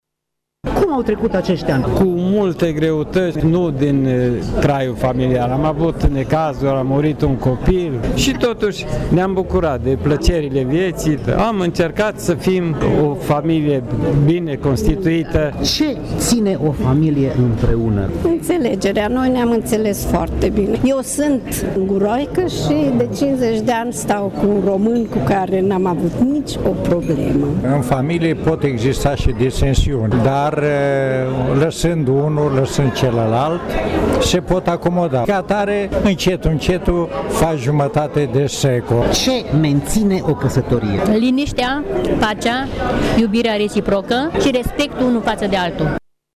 Emoționați și binedispuși, membrii cuplurilor ”de aur” au explicat că mariajul lor a fost menținut prin respect reciproc, înțelegere, dragoste și înțelepciunea compromisului.
Evenimentul “ Nunta de Aur” a avut loc la Centrul Cultural “M. Eminescu”.